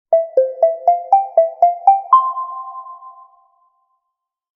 Gentle Notification Tone Sound Effect
Description: Gentle notification tone sound effect. A soft and subtle notification melody, ideal for app alerts, reminders, and device notifications. Creates a calm and pleasant alert experience for users.
Gentle-notification-tone-sound-effect.mp3